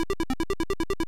Charamario_MarioOriginal_walk1.wav